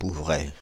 Pouvrai (French pronunciation: [puvʁɛ]
Fr-Pouvrai.ogg.mp3